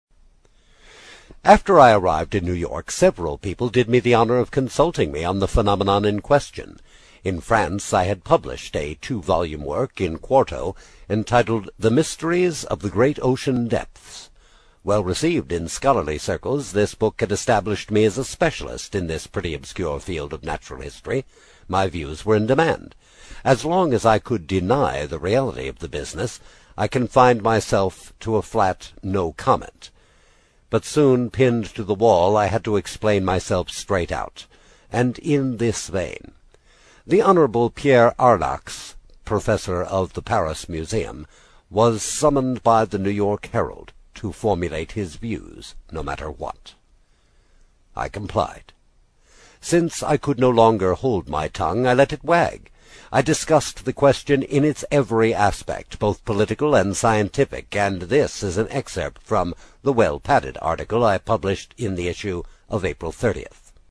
英语听书《海底两万里》第15期 第2章 正与反(4) 听力文件下载—在线英语听力室